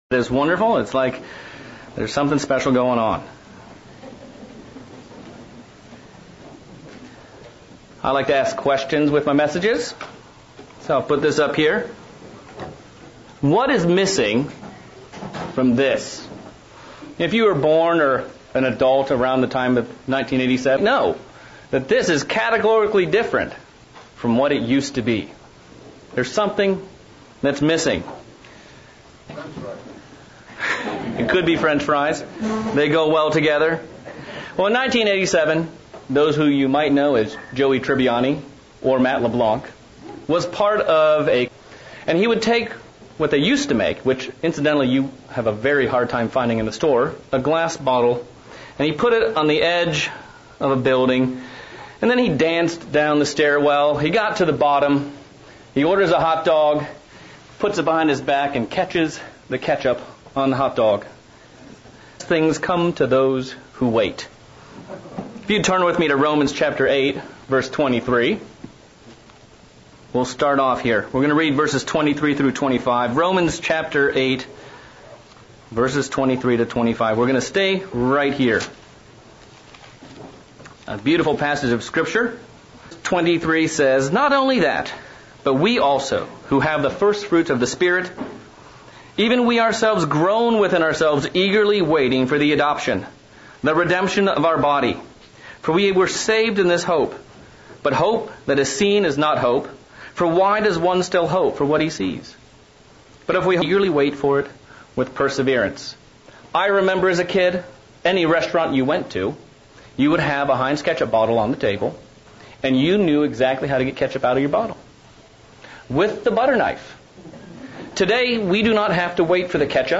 Sermonette looking at the subject of hope.  What is true real hope from a Biblical point of view and how can we keep it strong?